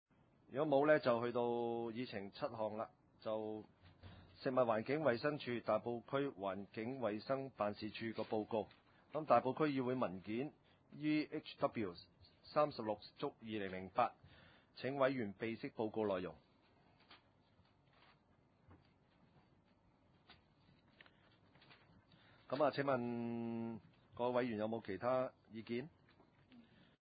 環境、房屋及工程委員會2008年第三次會議
地點：大埔區議會秘書處會議室